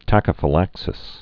(tăkə-fĭ-lăksĭs)